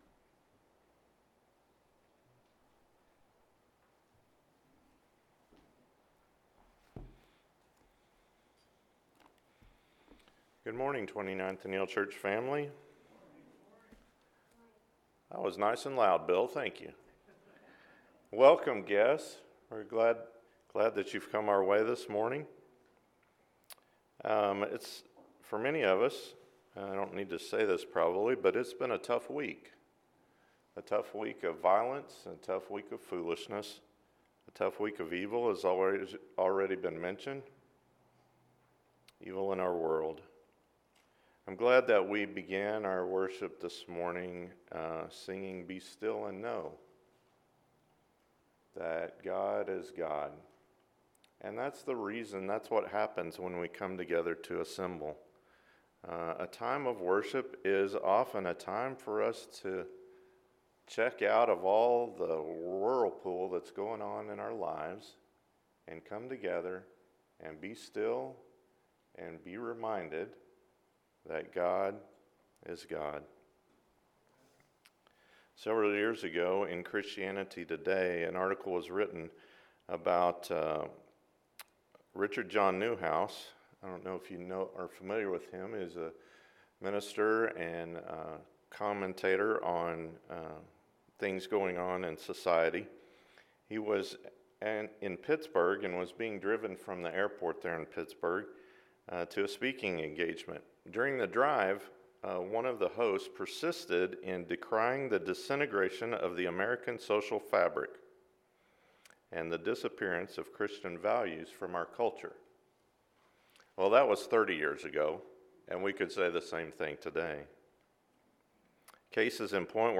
The Secret Center – Sermon